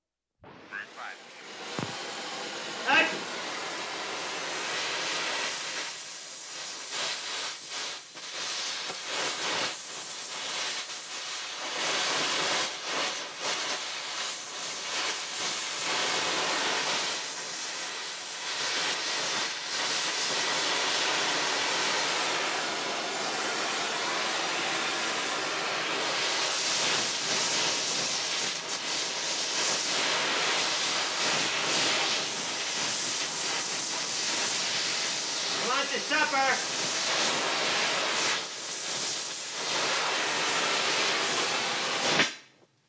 wings flap and air pressure sounds uncut